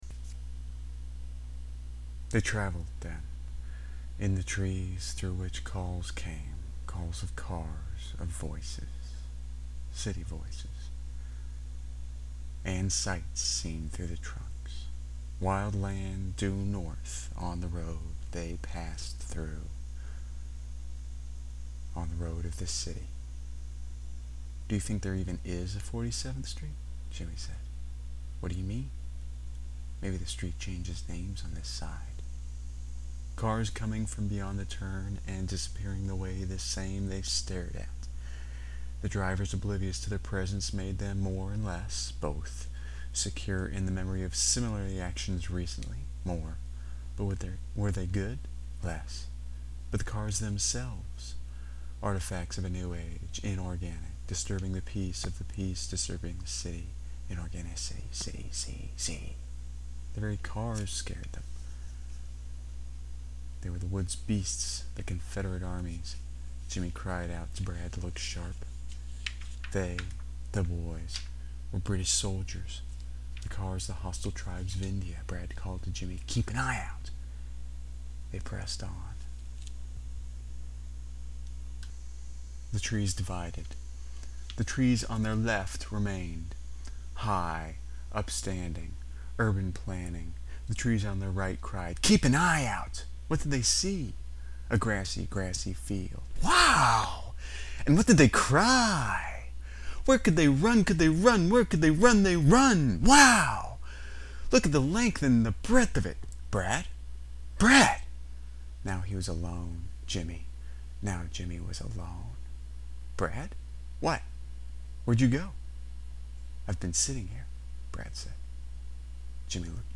Audio recordings of the first chapter
Also just past halfway are a few phrases in German.
As opposed to mangled by terrible singing. Chapter 1: Part 1 (17 minutes) Chapter 1: Part 2 (14 minutes) Chapter 1: Part 3 (15 minutes)